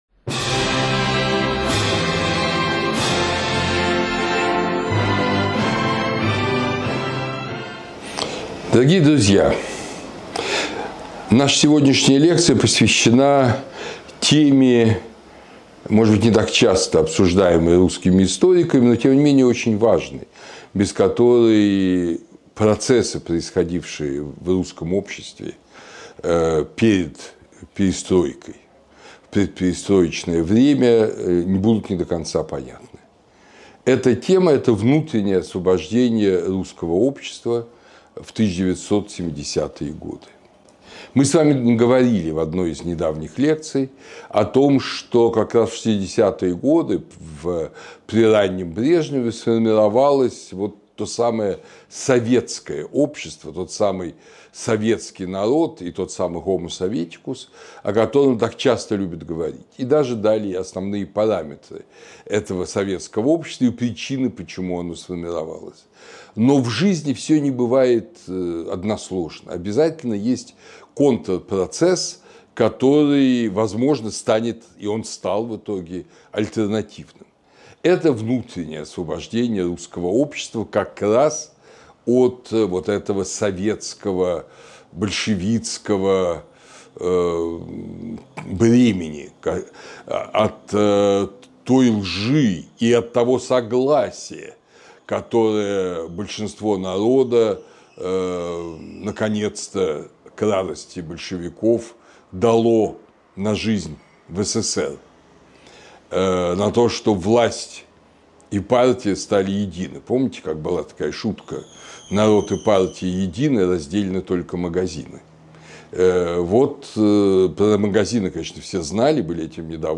Лекцию читает Андрей Зубов